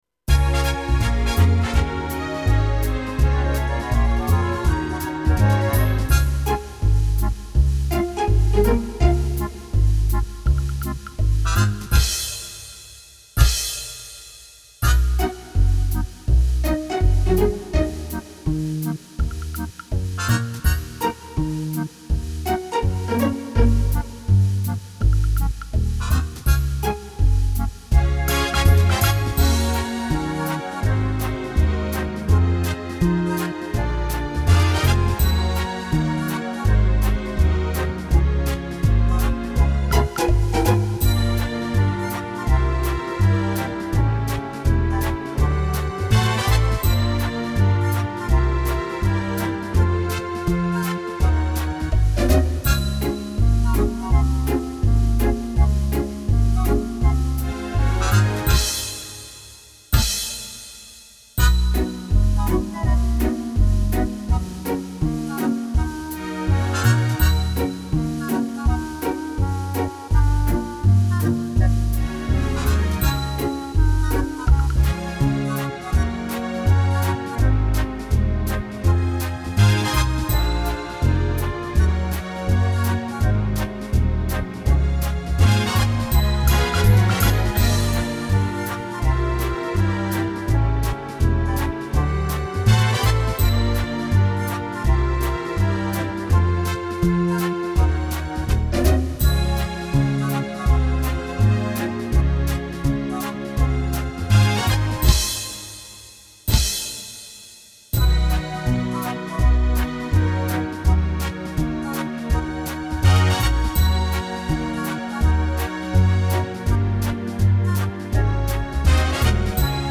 • Качество: Хорошее
• Категория: Детские песни
караоке
минусовка